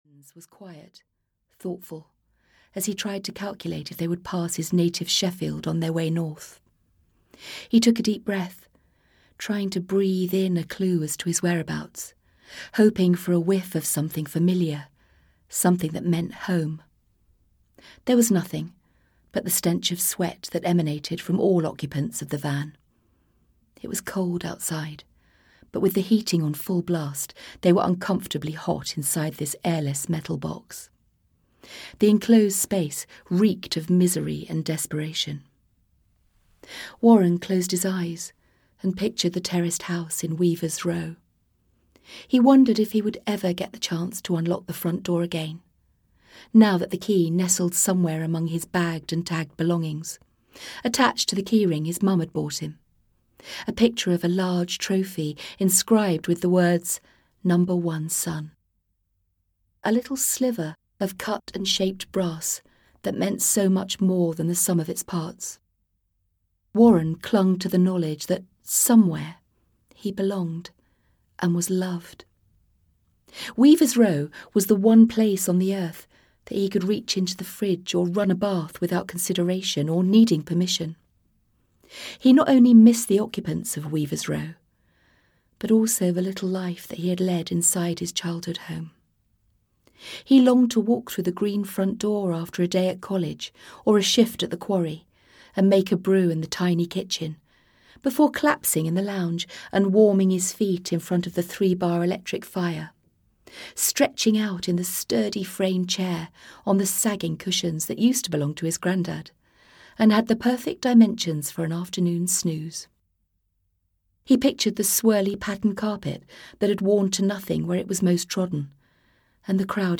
Ukázka z knihy
• InterpretAmanda Prowse